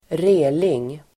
Ladda ner uttalet
Uttal: [²r'e:ling]
reling.mp3